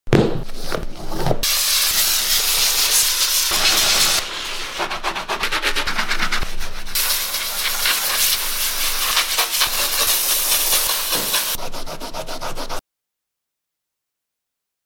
Jordan 1 Sneaker Cleaning Sound Effects Free Download